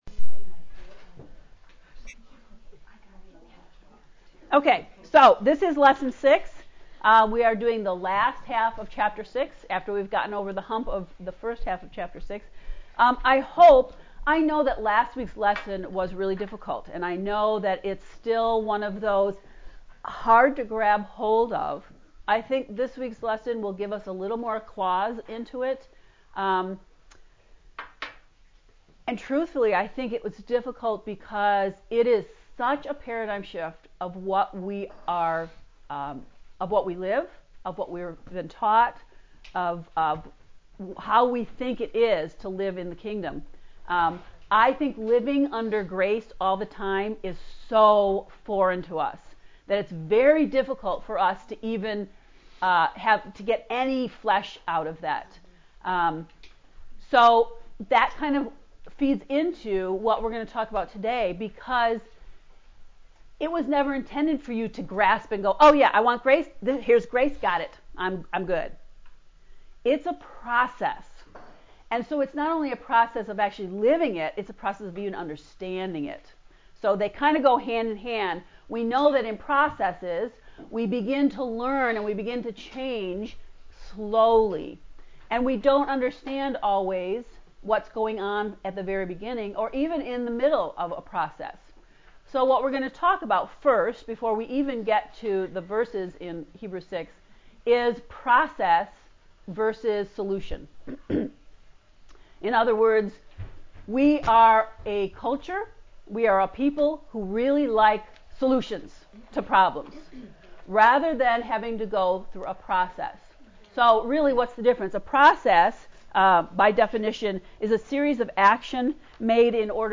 heb-ii-lecture-6.mp3